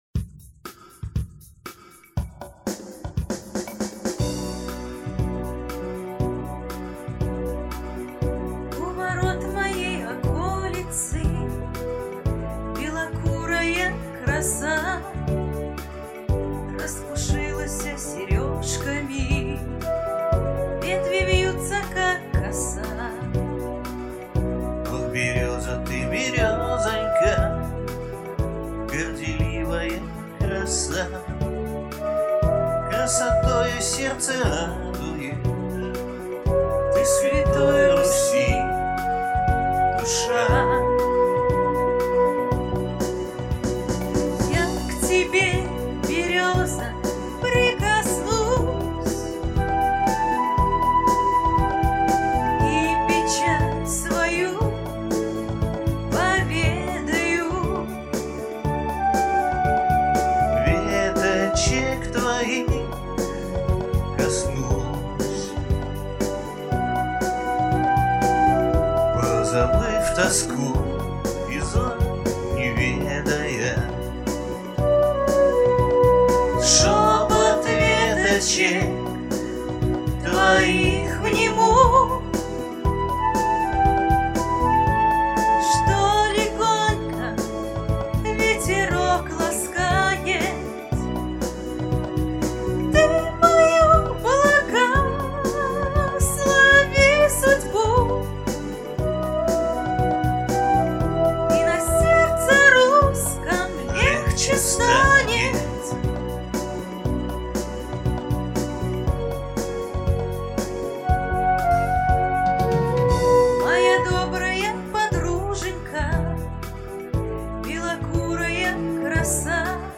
Жанр: новый русский шансон